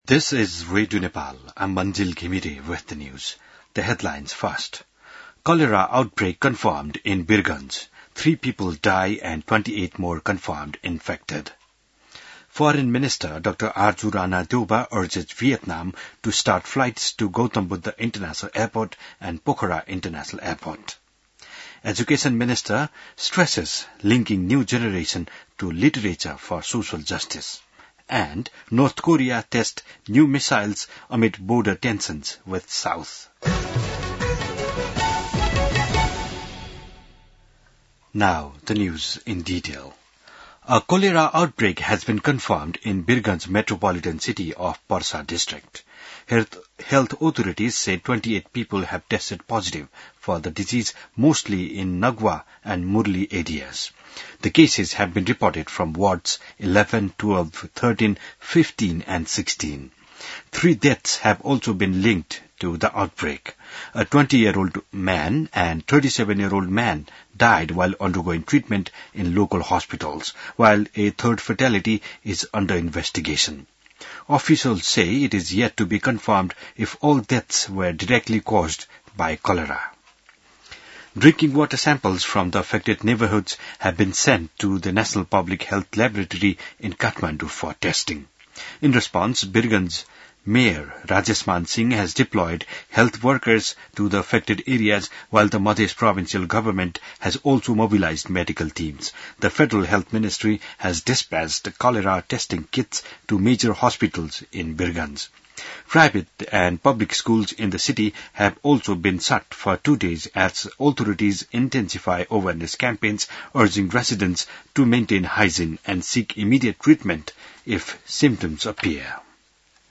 An online outlet of Nepal's national radio broadcaster
बिहान ८ बजेको अङ्ग्रेजी समाचार : ८ भदौ , २०८२